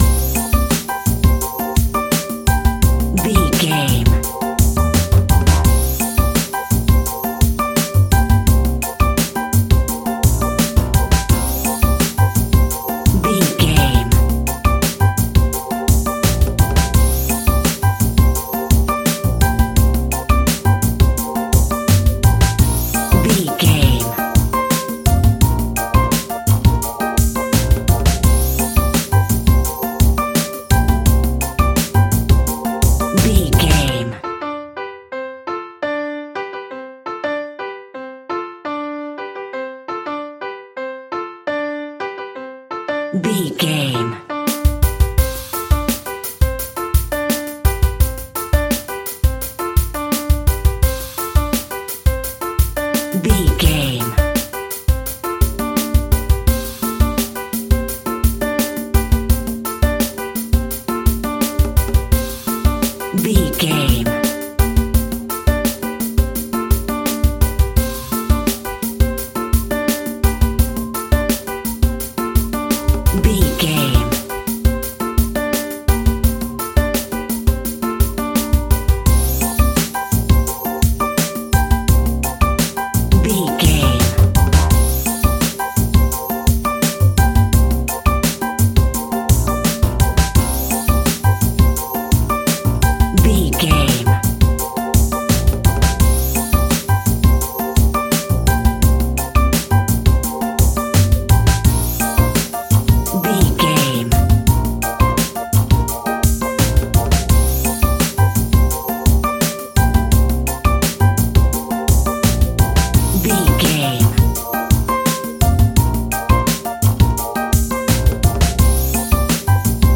Exotic, spicy and from another world!
Aeolian/Minor
energetic
electric guitar
bass guitar
drums
hammond organ
percussion